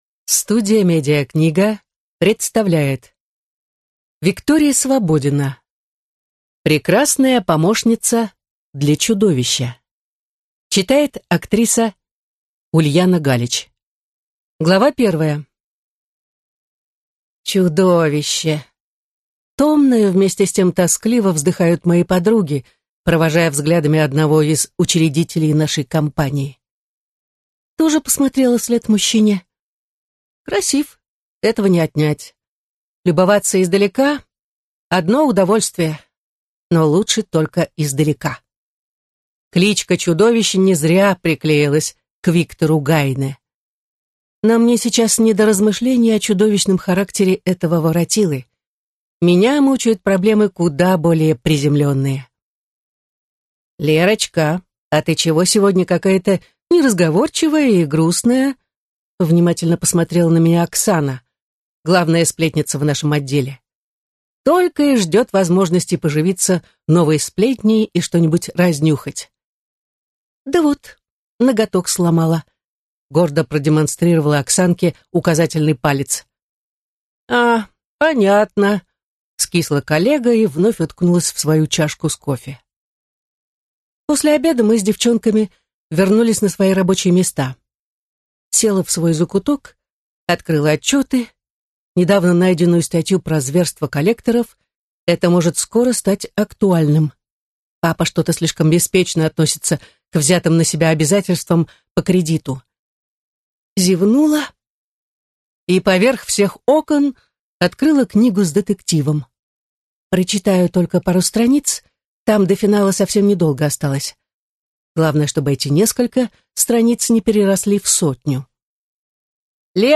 Аудиокнига Прекрасная помощница для чудовища | Библиотека аудиокниг